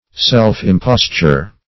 Search Result for " self-imposture" : The Collaborative International Dictionary of English v.0.48: Self-imposture \Self`-im*pos"ture\, n. Imposture practiced on one's self; self-deceit.